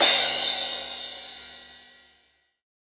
• 2000s Large Room Single Cymbal Hit C# Key 03.wav
Royality free cymbal sound tuned to the C# note. Loudest frequency: 5954Hz